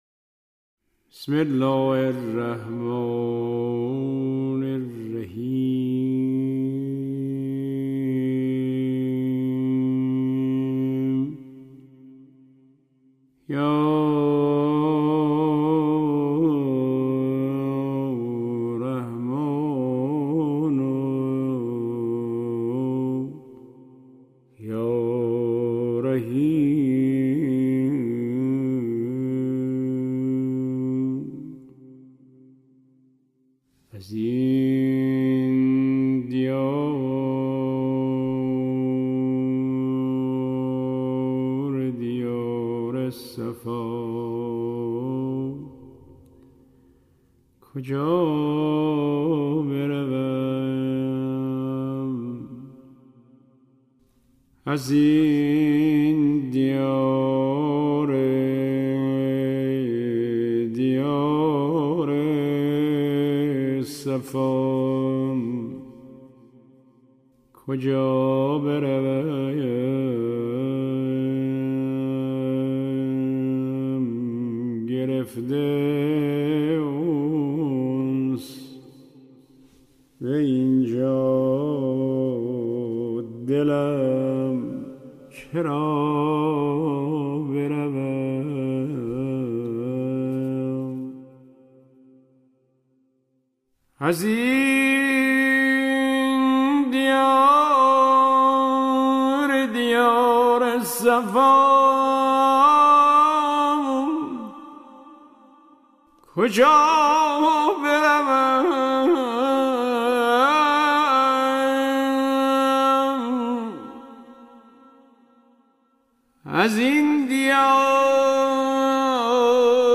گلچین مداحی های شهادت امام رضا(ع)